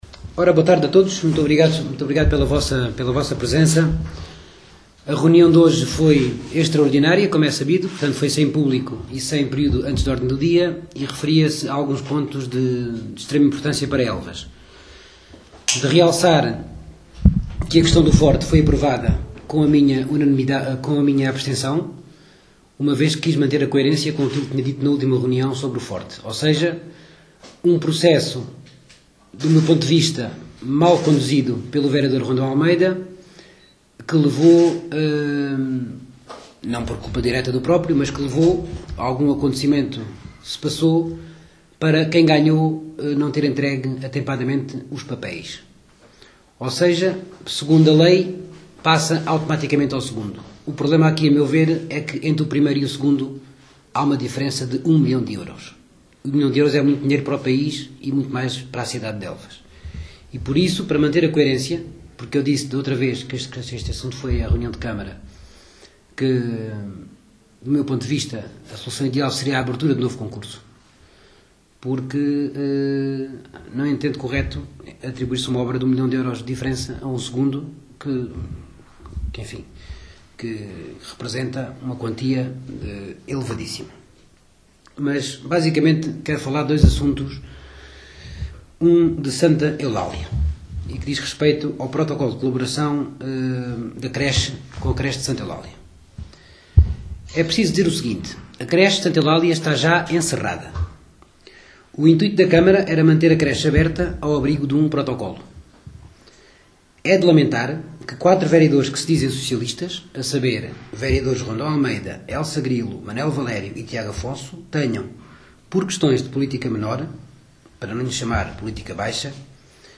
Vereador Tiago Abreu
Gravação na integra da conferência de imprensa do vereador do CDS Tiago Abreu a propósito da reunião extraordinária da Câmara Municipal de Elvas realizada esta tarde de segunda-feira, 4 de agosto em que o protocolo de colaboração com a Creche de Santa Eulália foi reprovado, com três votos a favor (presidente Nuno Mocinha e vereadores Vitória Branco e Tiago Abreu) e quatro votos contra (vereadores Elsa Grilo, Rondão Almeida, Manuel Valério e Tiago Afonso)